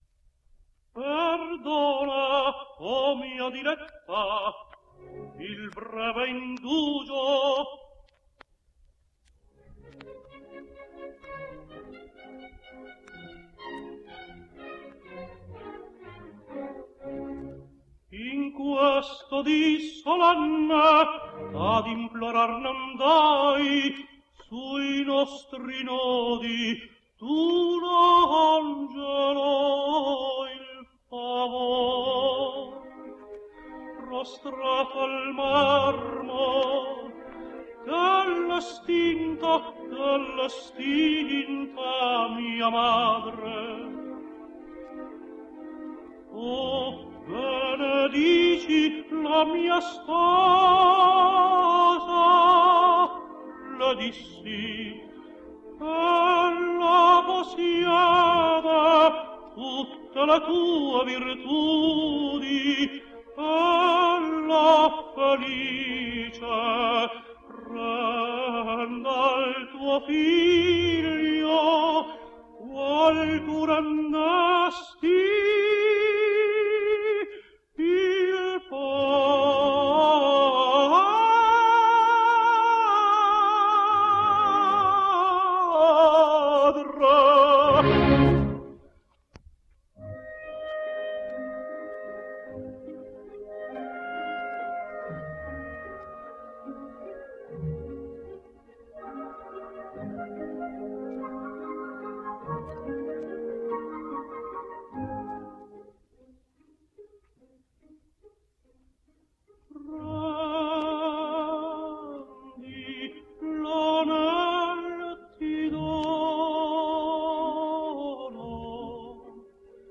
Italian Tenor.
So, here are two samples where he is joined by that fine Italian coloratura soprano Lena Pagliughi.